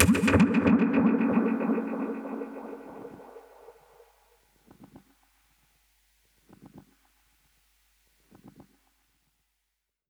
Index of /musicradar/dub-percussion-samples/95bpm
DPFX_PercHit_A_95-06.wav